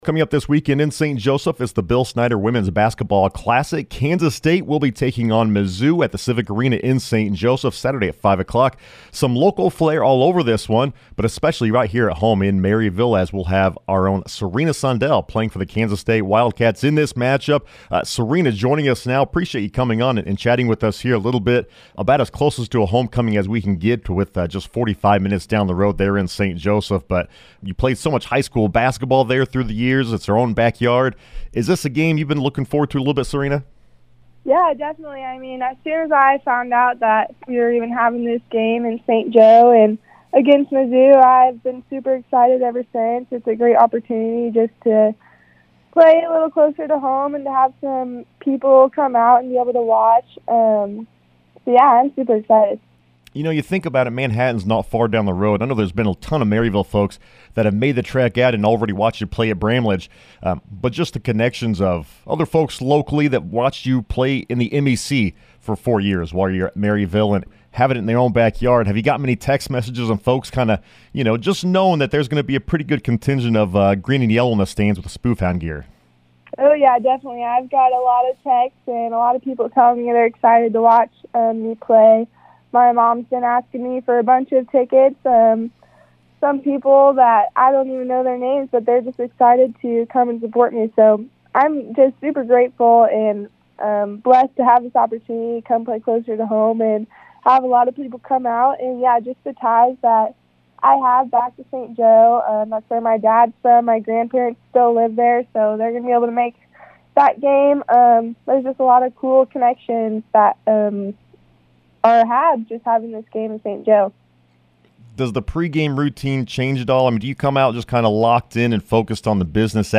News Brief
The full interview